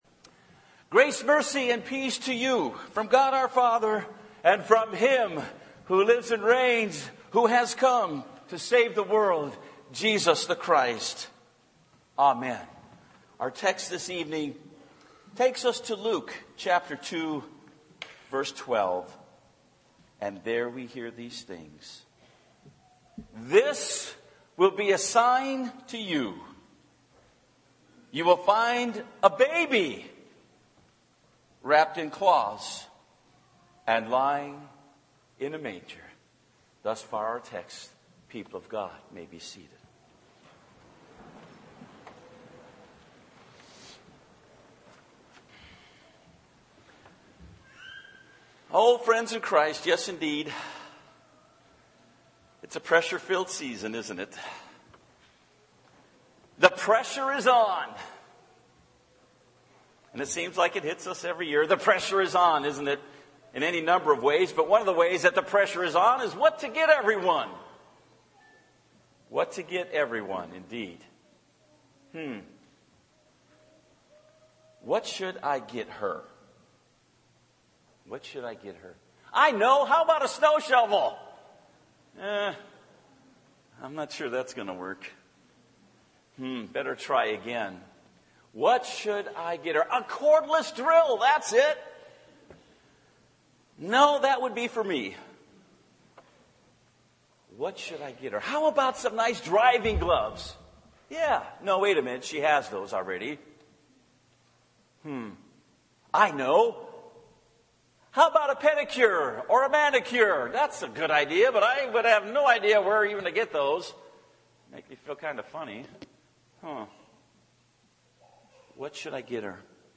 Audio Sermon
Christmas_Eve_2017.mp3